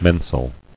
[men·sal]